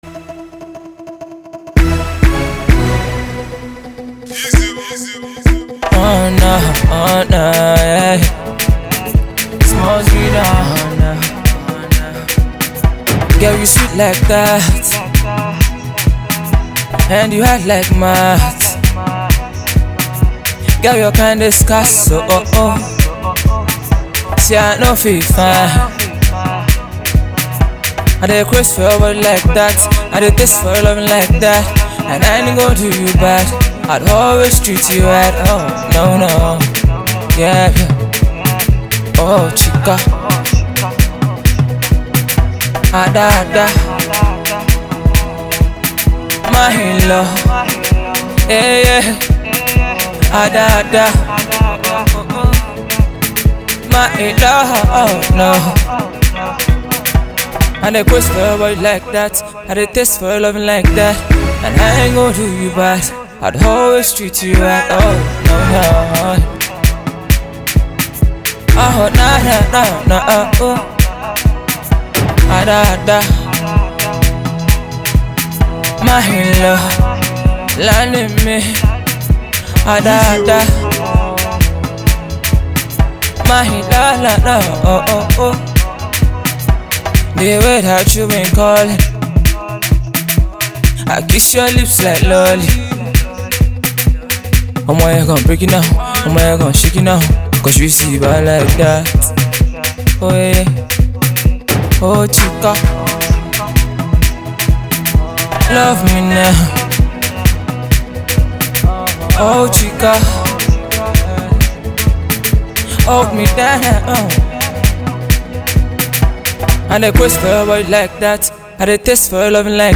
an Afro Pop uptempo track